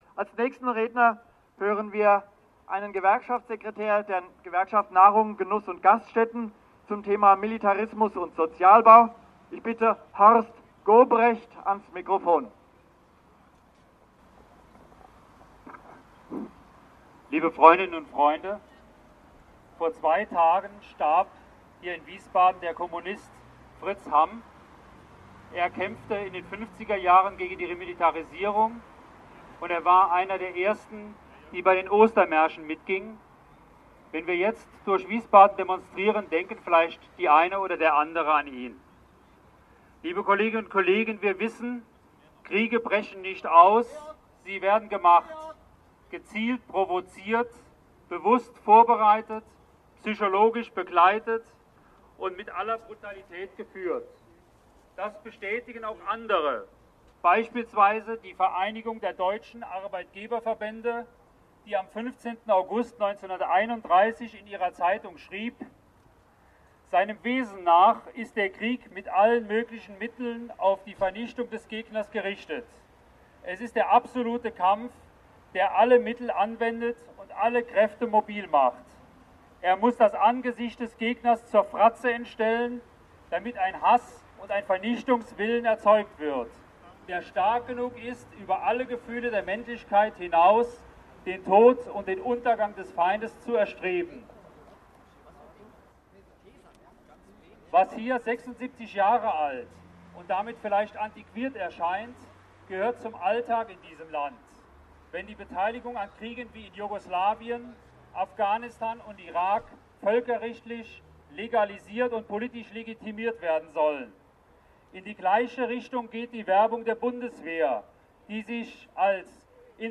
Rede als MP3